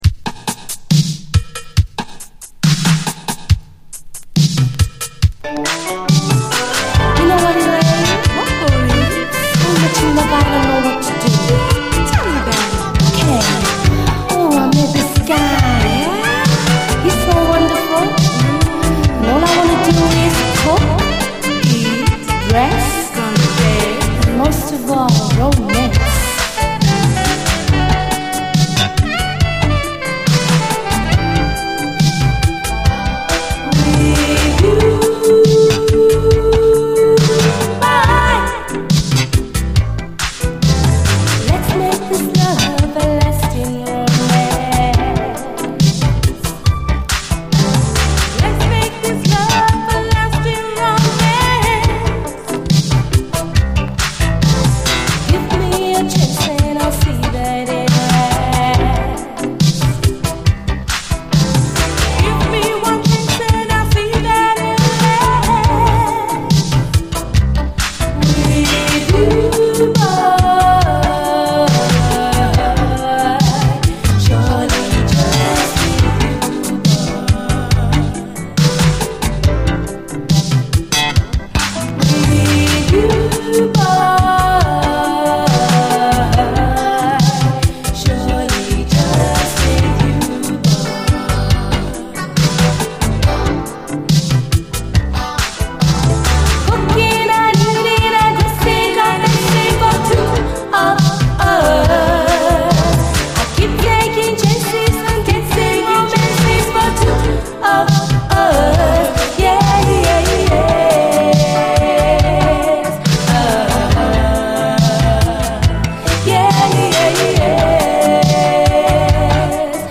SOUL, 70's～ SOUL, REGGAE
もはやレゲエでもない、最高のUK産80'Sシルキー・メロウ・ソウル！
同オケを使った別曲のメロウ・レディー・ソウル！